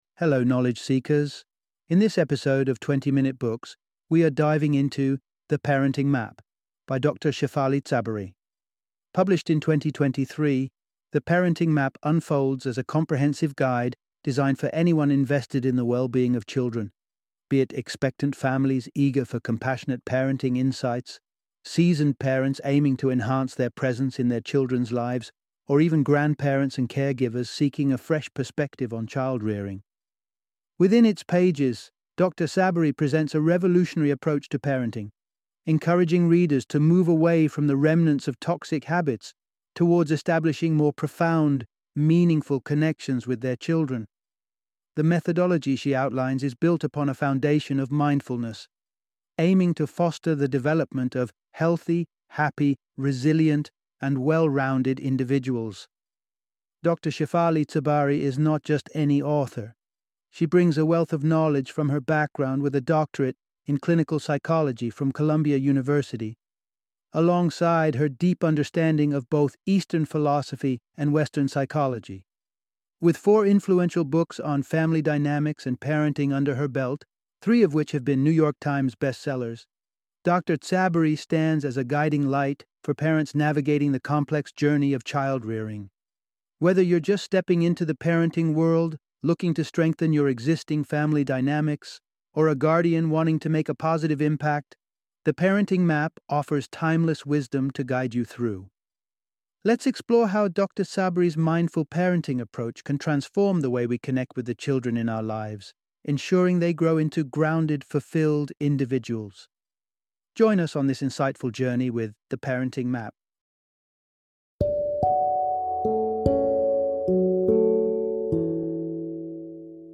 The Parenting Map - Audiobook Summary